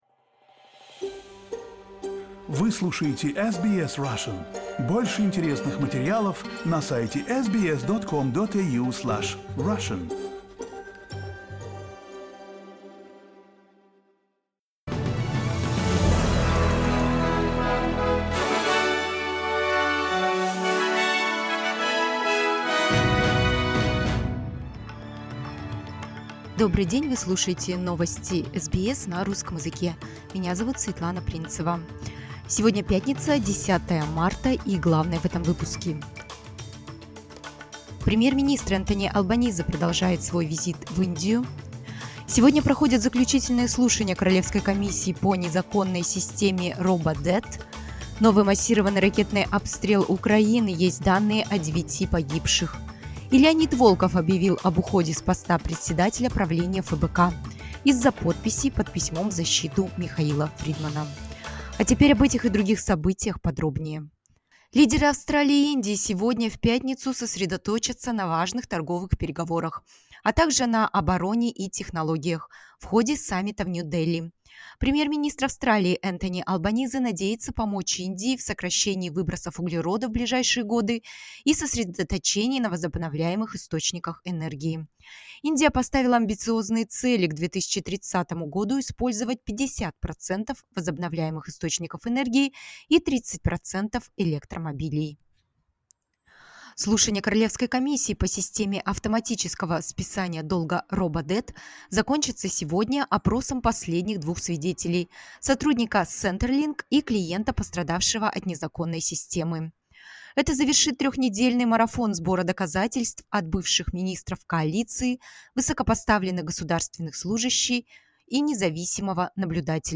SBS news in Russian — 10.03.2023